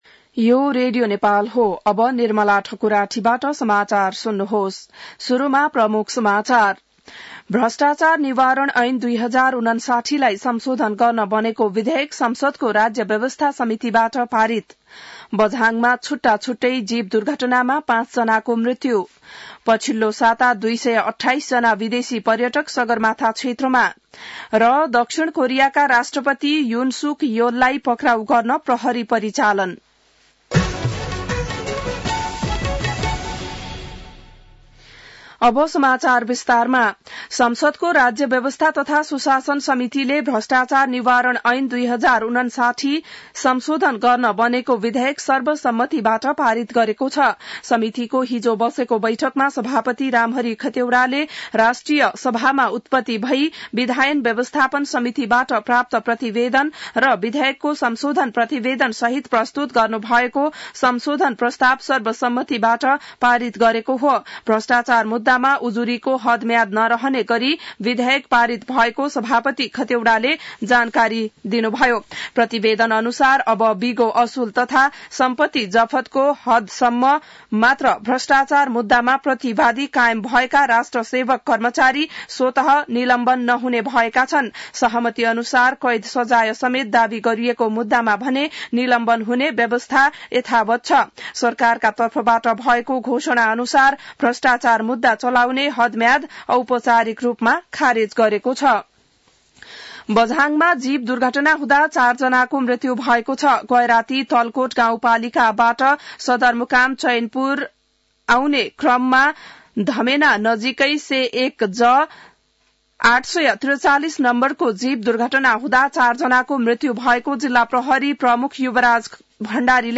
बिहान ९ बजेको नेपाली समाचार : २० पुष , २०८१